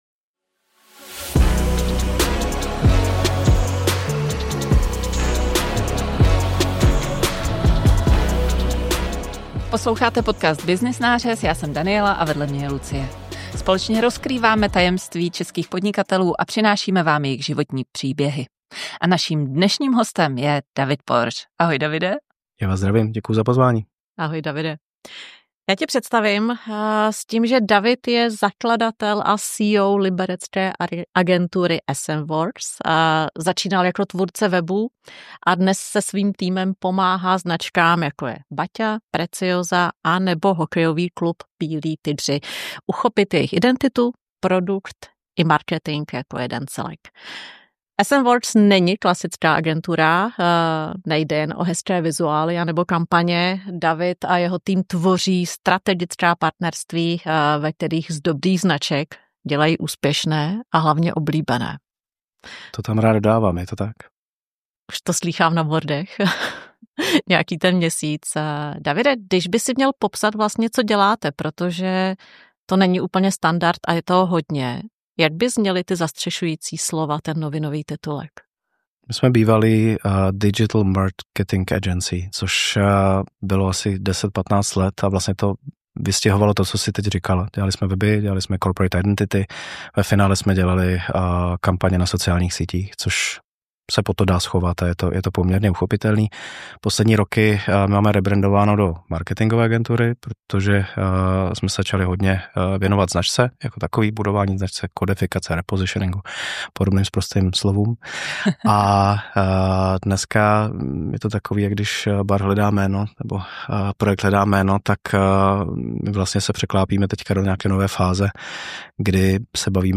Poslechněte si celý rozhovor a zjistěte, proč je lepší stavět značku pomalu a poctivě než rychle a napůl.